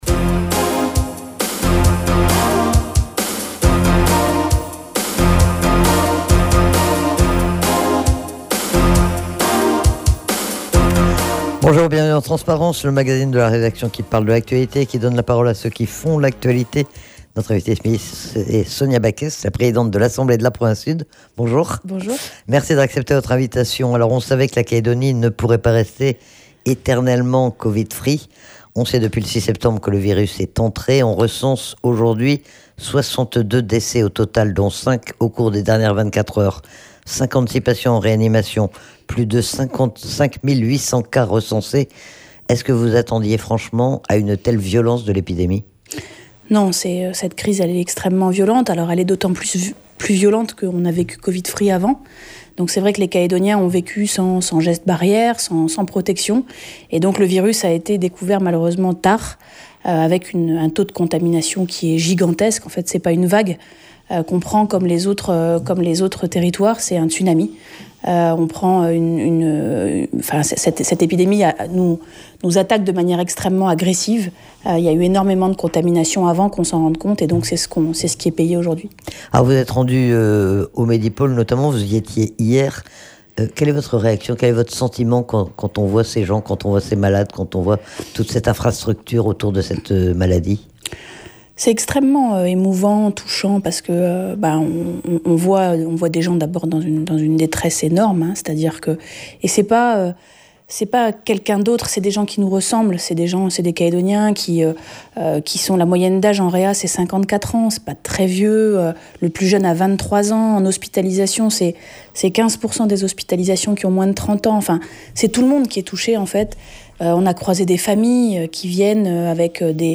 La présidente de la Province Sud est interrogée sur la gestion de la crise sanitaire par sa collectivité et notamment sur les aides qui sont apportées aux entreprises, mais aussi plus globalement sur l'actualité calédonienne.